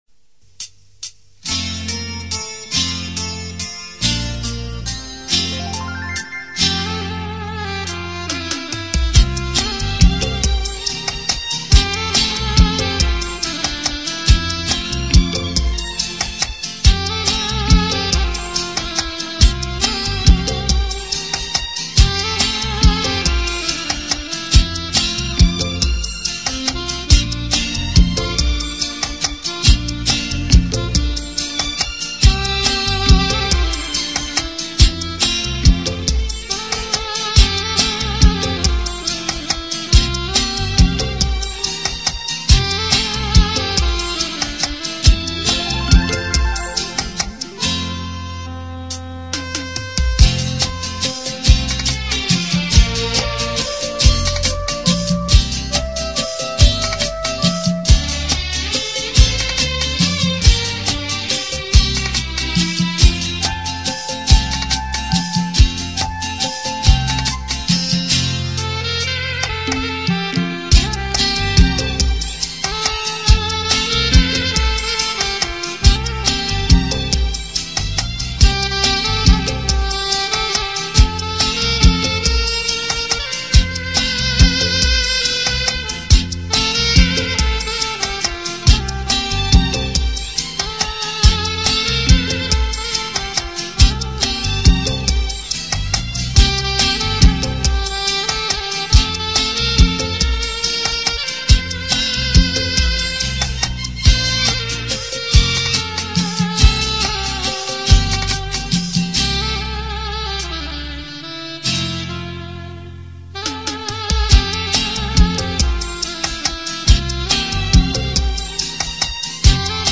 Instrumental Songs > Old Bollywood